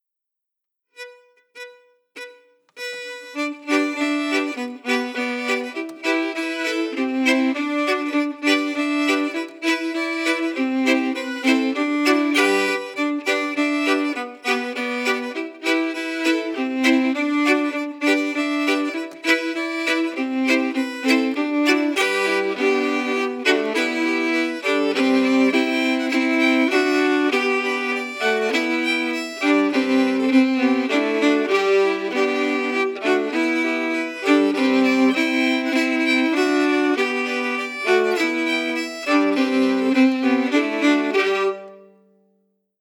Key: G
Form: Reel
Harmonies emphasis emphasis